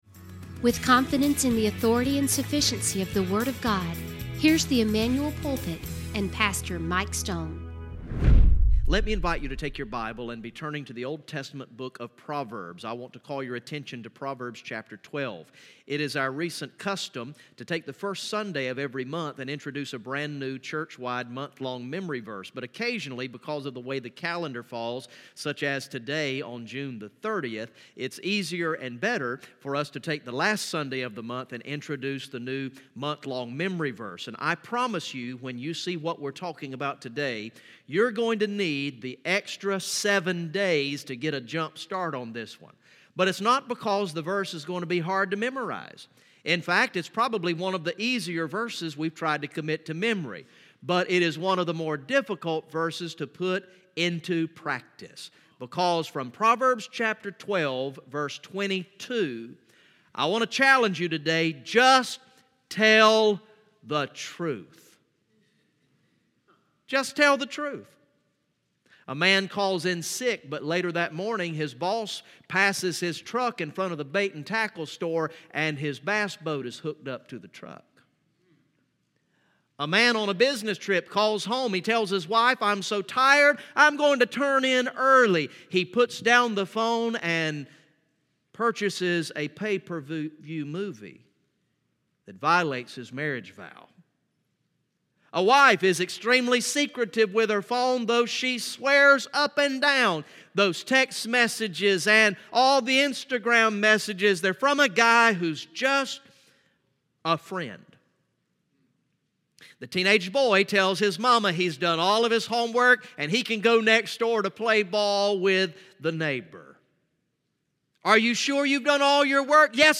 From the morning worship service on Sunday, June 30, 2019